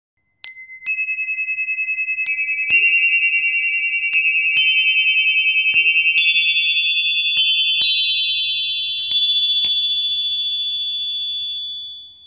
This crystal sound carries frequencies that help bring your body, mind, and spirit back into balance.